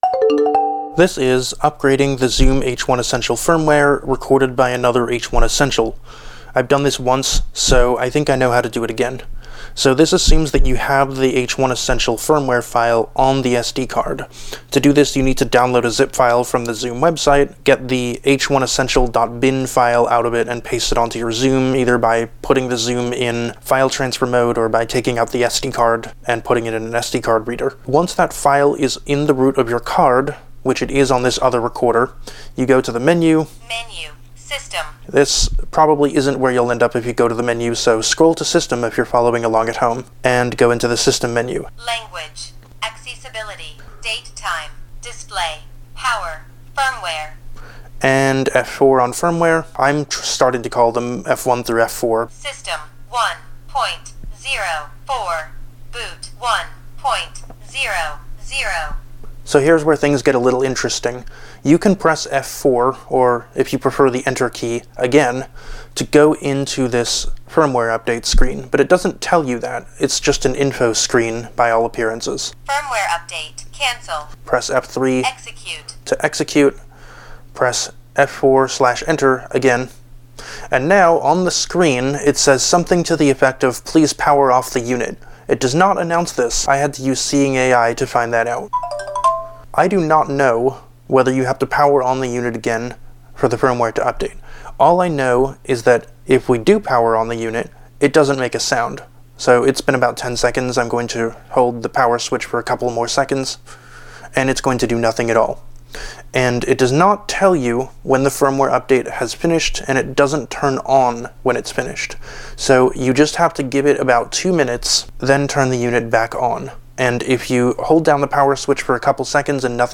I have two H1Essential recorders so I made a short recording of the firmware update process on the second recorder, in case it helps someone more than the anti-useful text instructions.
The recorder should announce the current firmware version.
The recorder will say "firmware update, cancel."
This will play the standard power off sound.
This will make no sound.